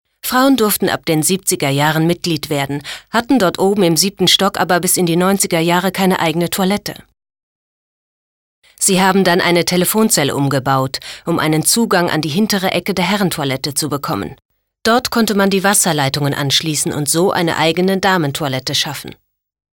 deutsche Sprecherin mit einer warmen, sinnlichen, kraftvollen, wandelbaren Stimme.
Sprechprobe: Werbung (Muttersprache):